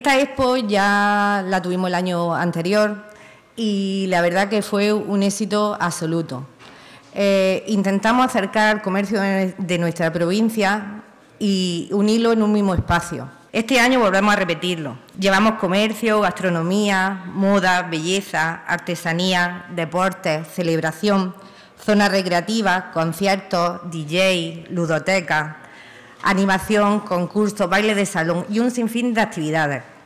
19-02_vinos_concejala.mp3